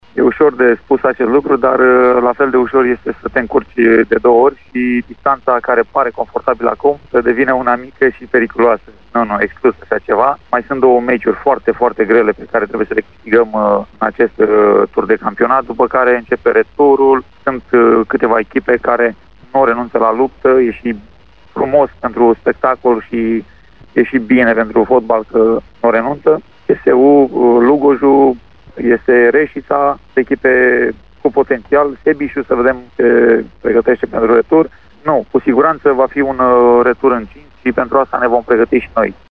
a vorbit despre echipa sa într-un interviu pentru Radio Timișoara